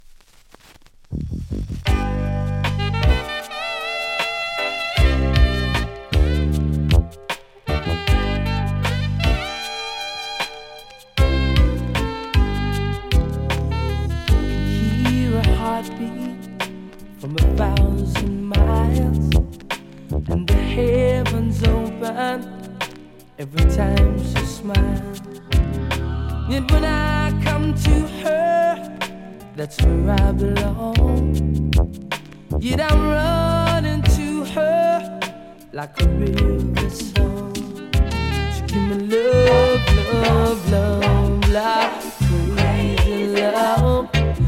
恋人から注がれる愛に感謝し、心が安らぐ様子を穏やかに歌ったラブソング♪
序盤キズによりノイズ感じますがプレイは問題無いレベル。